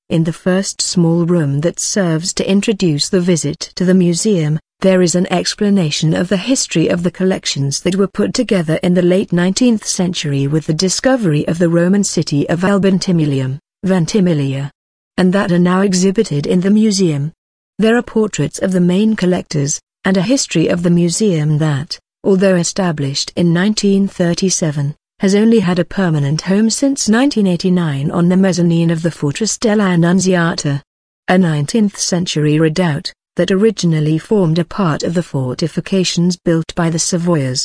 ENG Audioguide Room 1